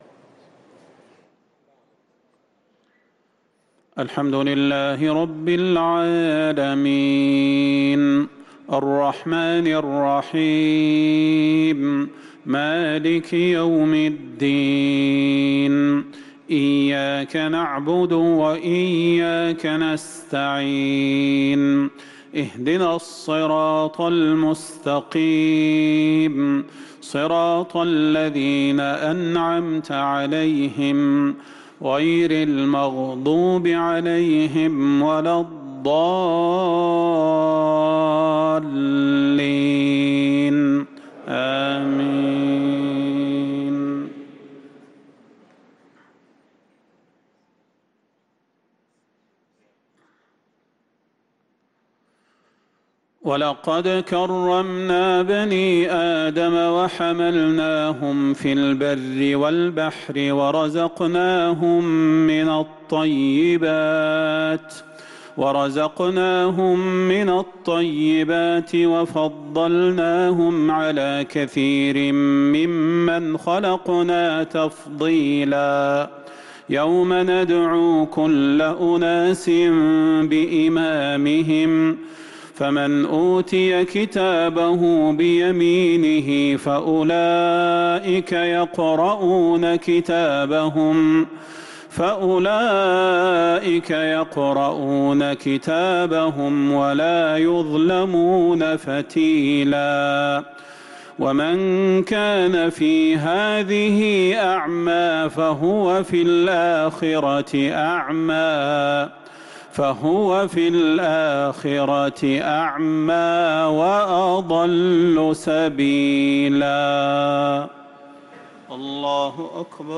صلاة العشاء للقارئ صلاح البدير 23 ربيع الأول 1445 هـ
تِلَاوَات الْحَرَمَيْن .